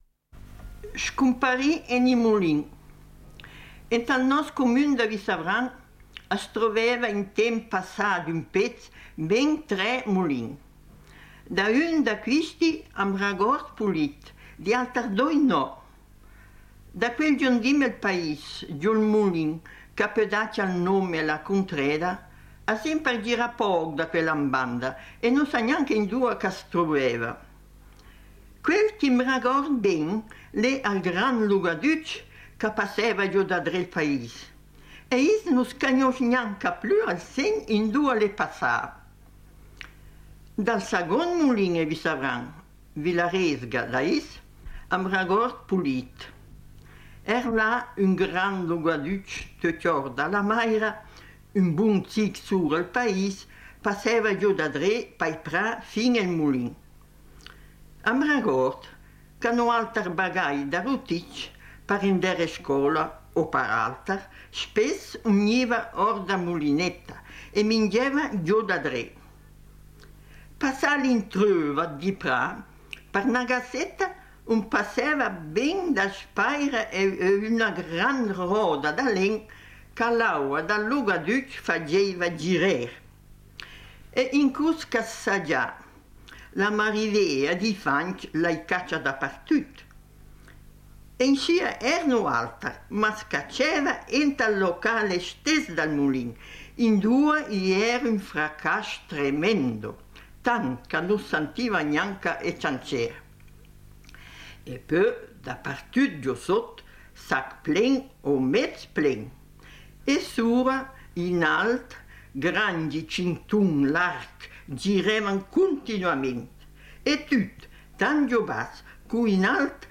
in dialetto bregagliotto